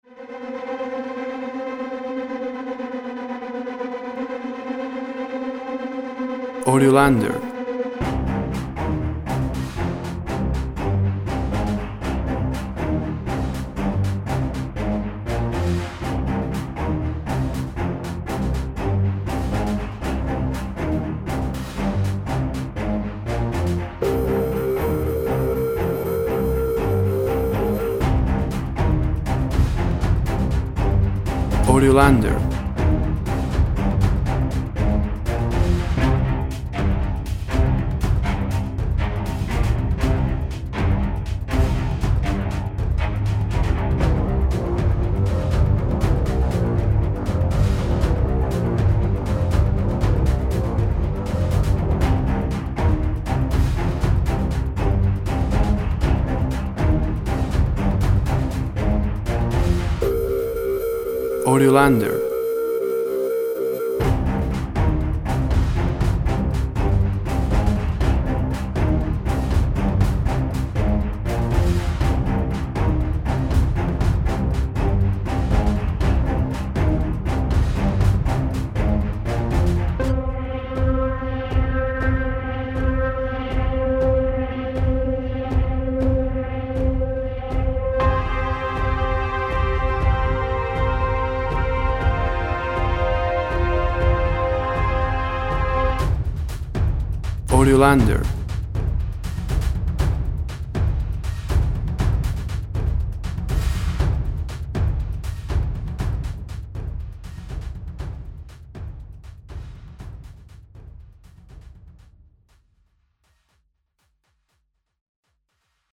Action music set in a flight crisis.
Tempo (BPM) 120